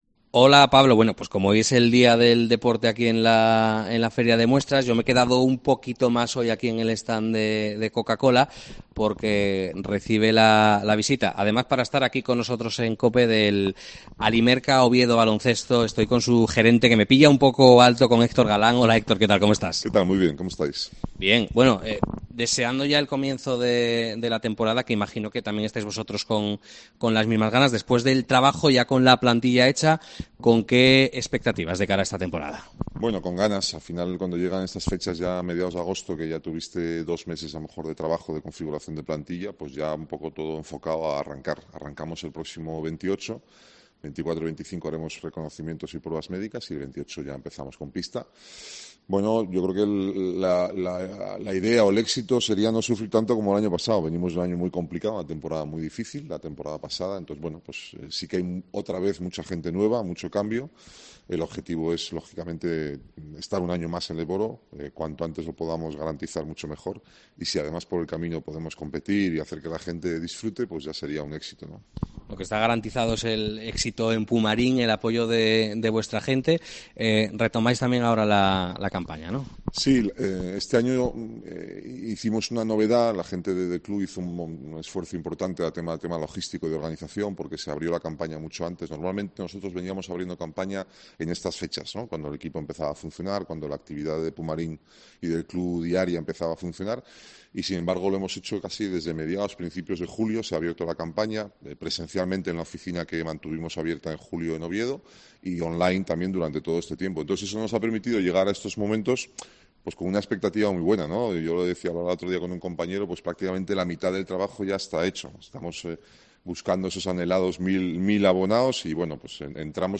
en COPE Asturias desde FIDMA